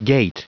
Prononciation du mot gait en anglais (fichier audio)
Prononciation du mot : gait